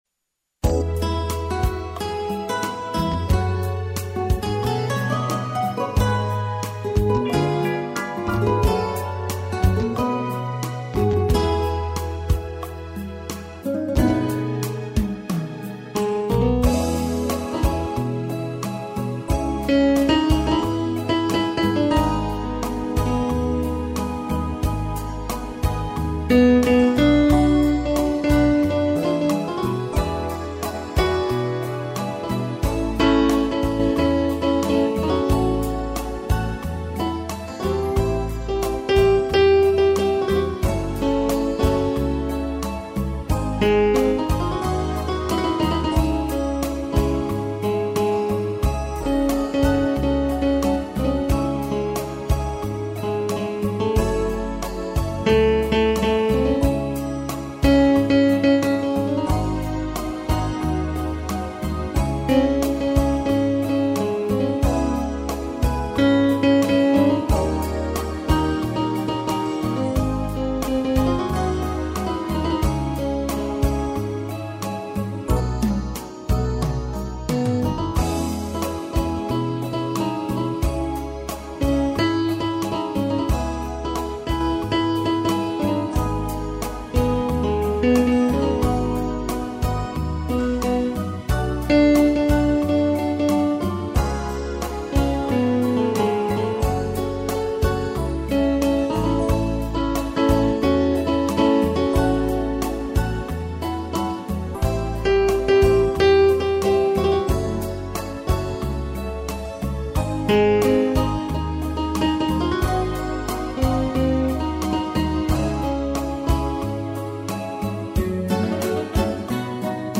Soneto 021 (letra: Shakespeare - Trad. Thereza - música e arranjo: Rocha) (instrumental)